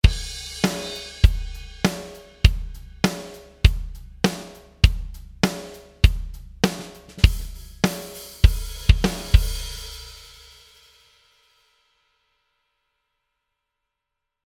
Mit den akustischen Drums hört es sich so an (Drive und Intensity in der 2-Uhr-Position) …
… extrem knallig, die Snare (und auch die Bassdrum). Der Anschlag wird vom FG-Bomber präzise moduliert, wiederum ohne unerwünschte Nebenwirkungen beim Blech.